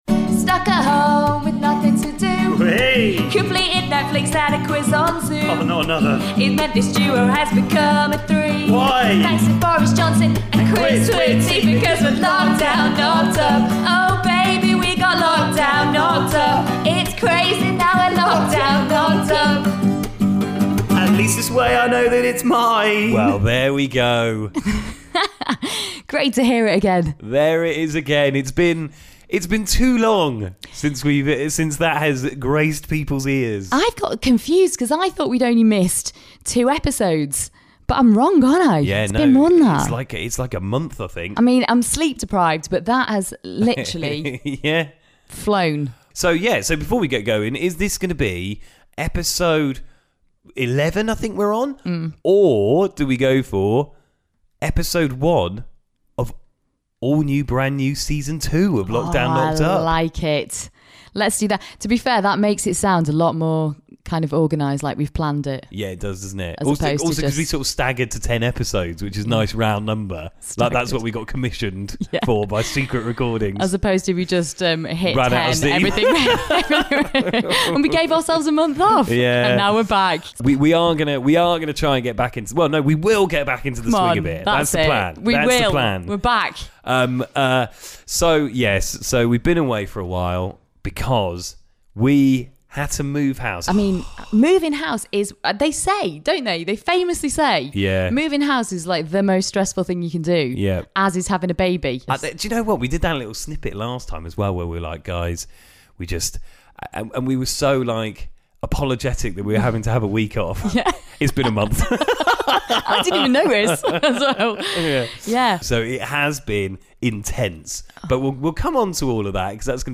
They're locked down and knocked up! … continue reading 13 episodes # Parenting # Kids And Family # Comedy # Secretly Recorded